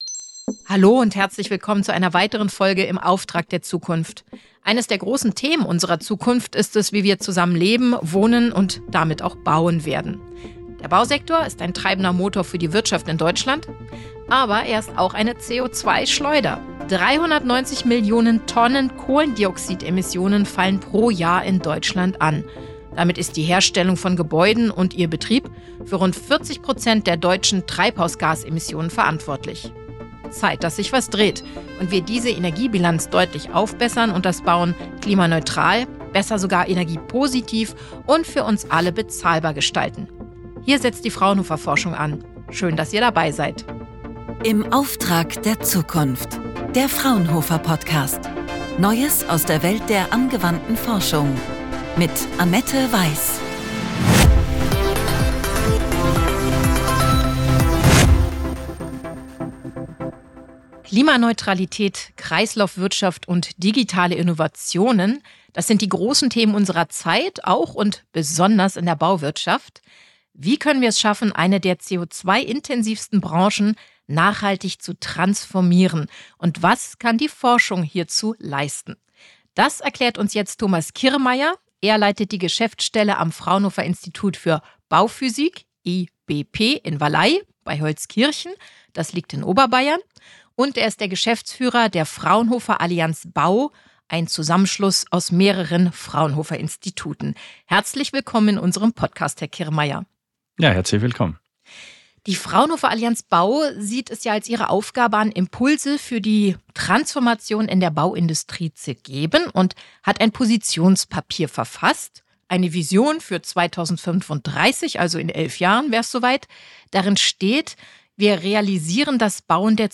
Mit zwei führenden Experten der Fraunhofer-Gesellschaft diskutieren wir Herausforderungen, zukunftsweisende Projekte und konkrete Lösungen für den Wandel in der Bauindustrie.